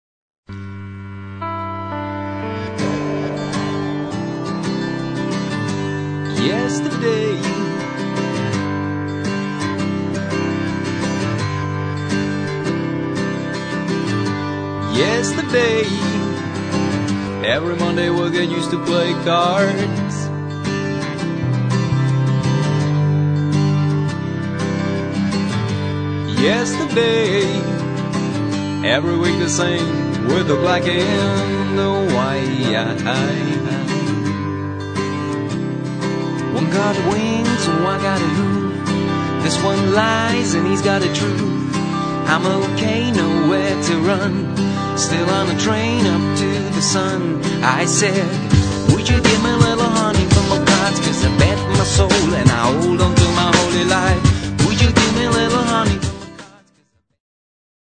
Crossoverová skupina s raketovým nástupem v roce 1994.